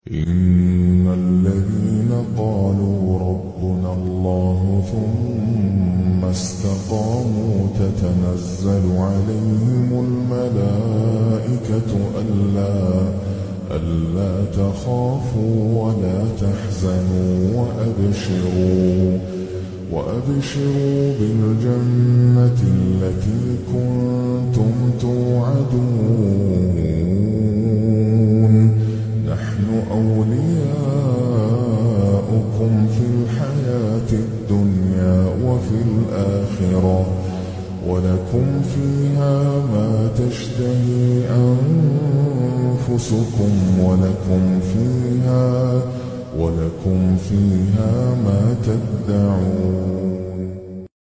Wear your headphones You will feel like you are in Masjid al-Haram 8D Audio 8D quran